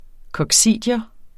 Udtale [ kʌgˈsiˀdjʌ ]